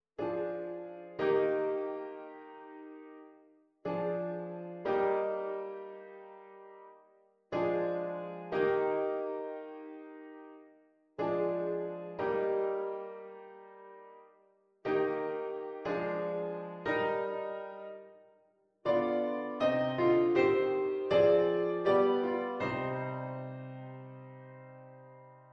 oplossing van VII6 met 4^ in de bovenstem